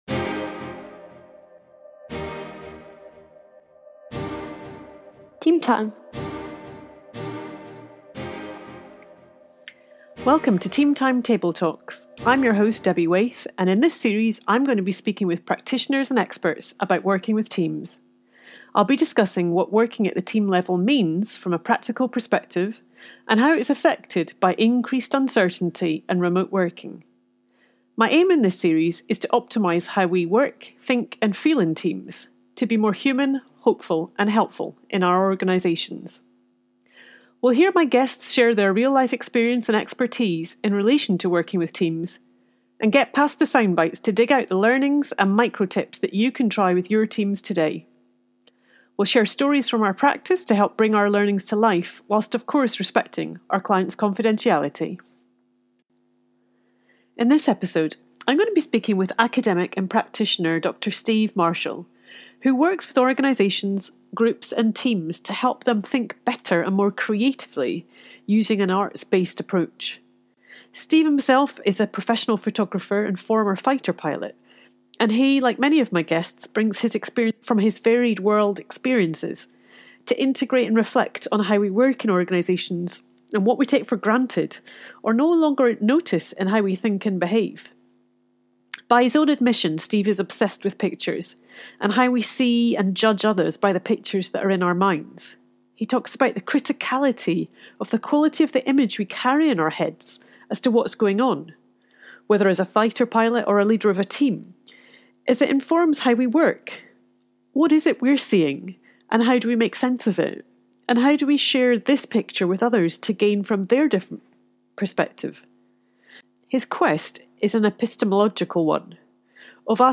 You'll hear from experienced practitioners as they share their work in this space - and challenge ourselves to consider what impact working virtually has on this work - as we explore interventions, techniques and processes that can help improve the functioning, health and performance of a team. This series aims to lift the lid on this often complex yet hidden work and share the experience of team practitioners more broadly.